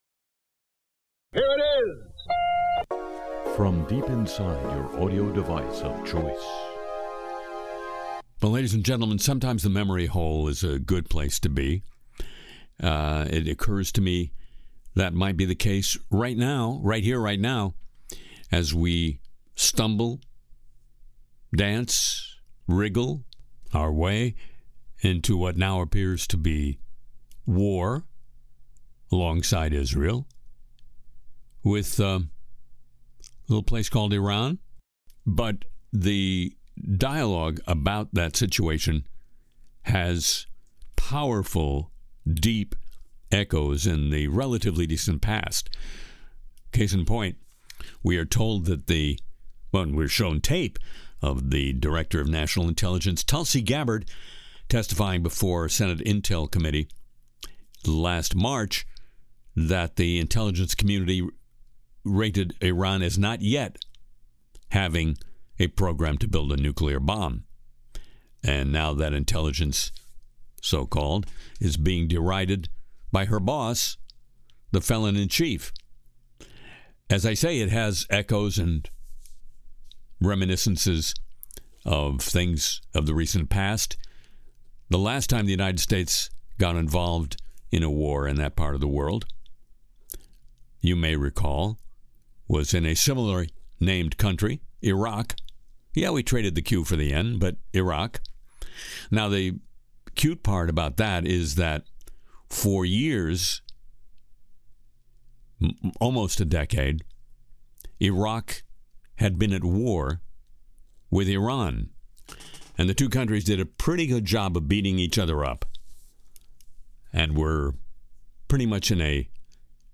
Tune into Harry Shearer’s Le Show (6/22/25) for biting Trump Truth Social satire, new song “I Dream Of Crypto,” SpaceX Starship explosion, Google’s AI YouTube drama, Vera Rubin Observatory’s satellite concerns, Catholic Church apology, microplastics risks, The Memory Hole on Iraq’s false intelligence, and more!